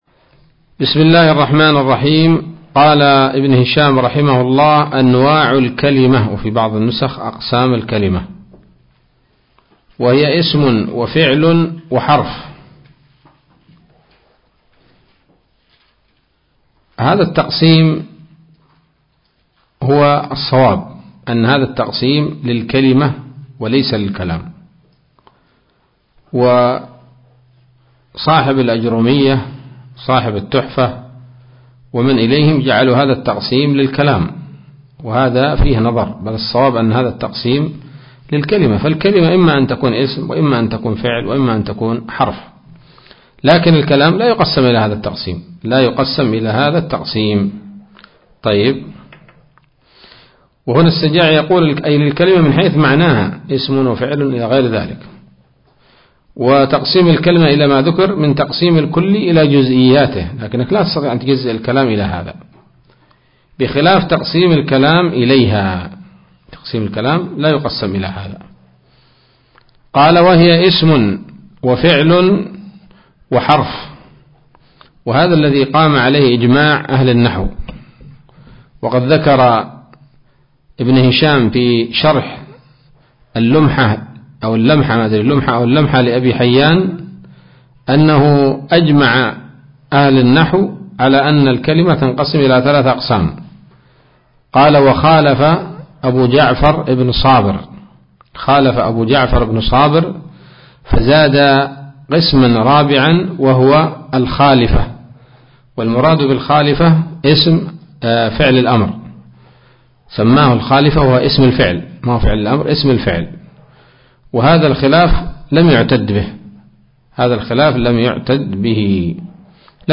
الدرس الثالث من شرح قطر الندى وبل الصدى [1444هـ]